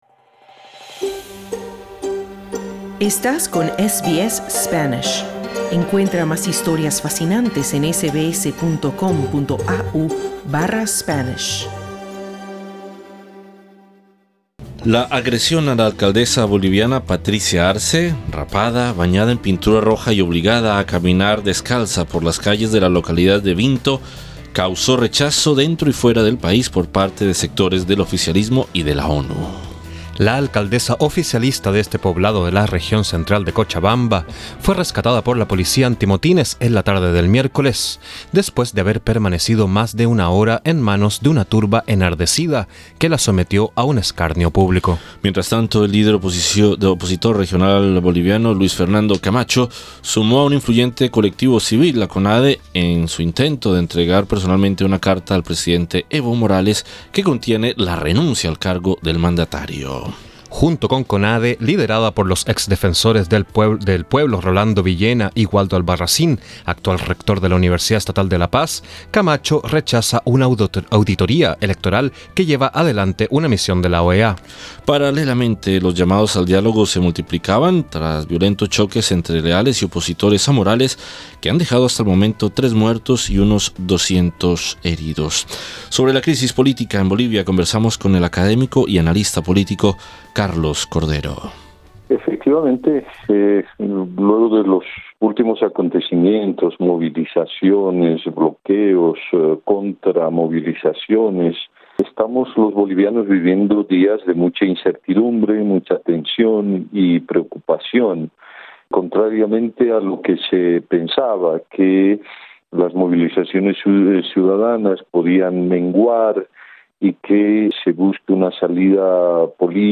SBS en español